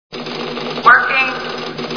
Star Trek TV Show Sound Bites